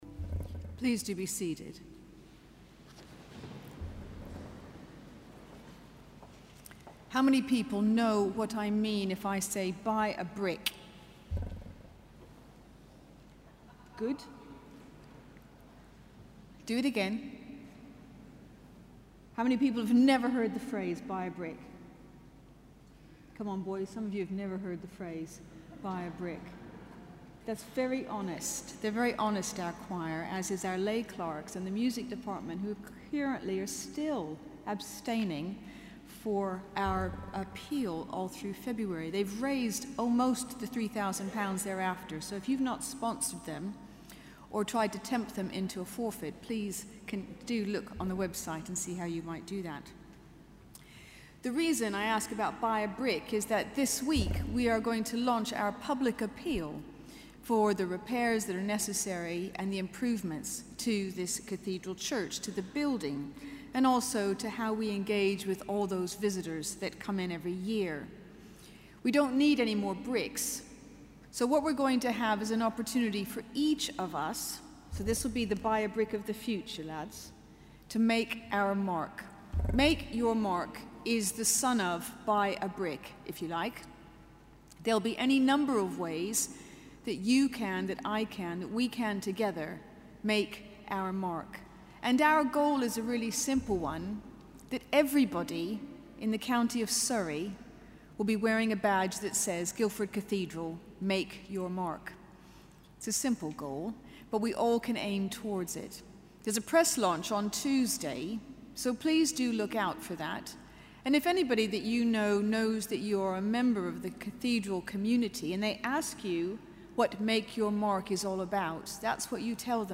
Sermon: Cathedral Eucharist 23 Feb 2014